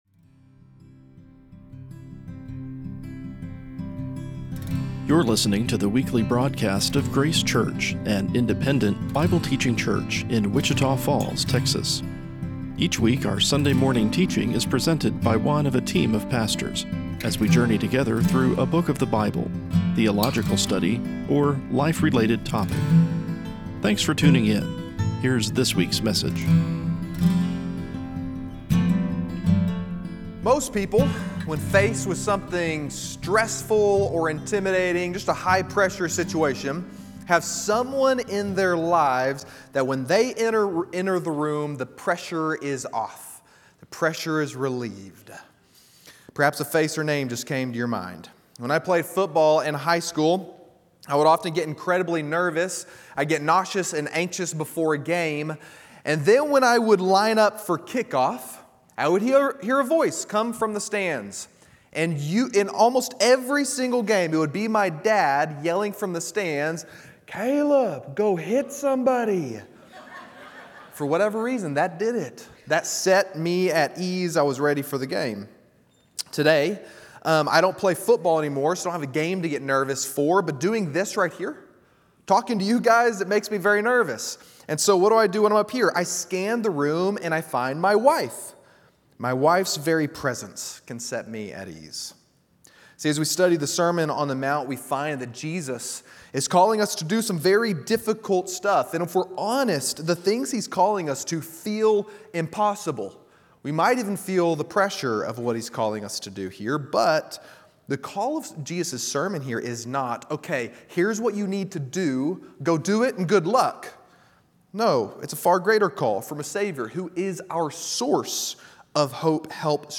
Our Sunday morning study of the “Sermon on the Mount” preached by Jesus, as recorded in the Gospel of Matthew. Discover the unexpected things He said about the nature of His Kingdom and why it is good news for those who dwell with Him.